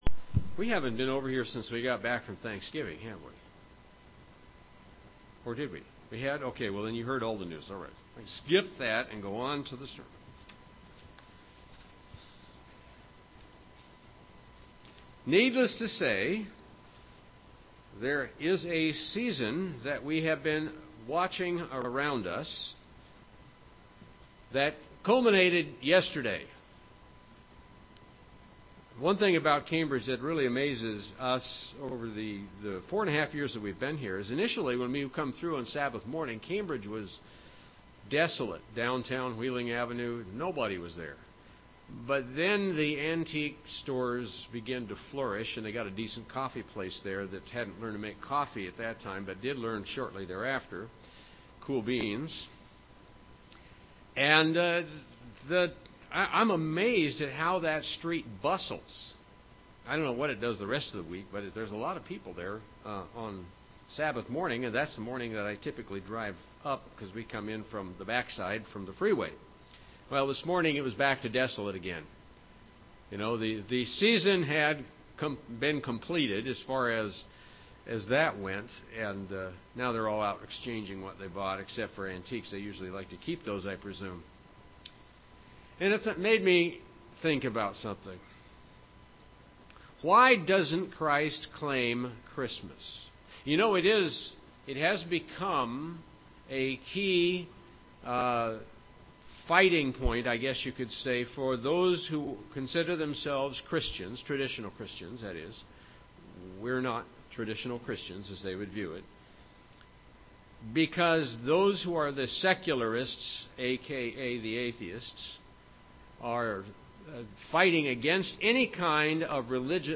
Print Seven Reasons Why Christ Doesn't Claim Christmas Seven Reasons Why Christ Doesn't Claim Christmas UCG Sermon Studying the bible?